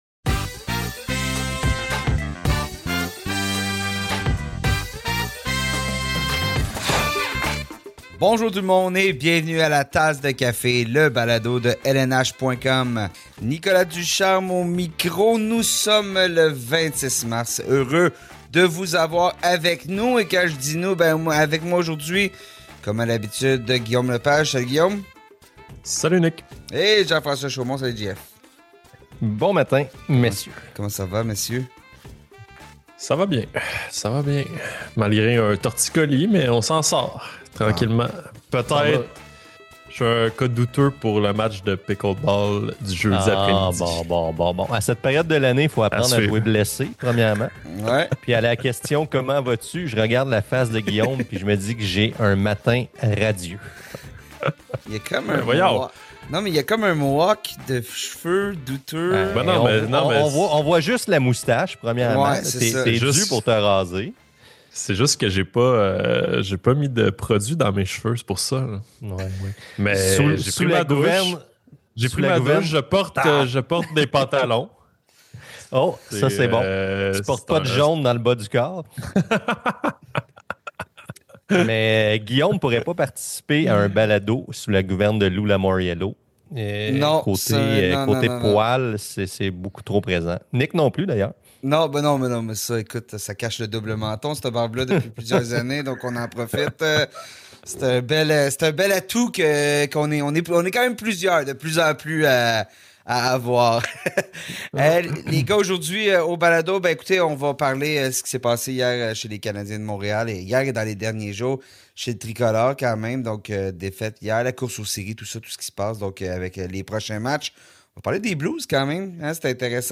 Qu’est-ce qui a permis à Pierre-Luc Dubois de rebondir à Washington après une saison difficile l’année dernière à Los Angeles? L’attaquant des Capitals nous l’explique dans cet épisode de La tasse de café.
Entretien avec Pierre-Luc Dubois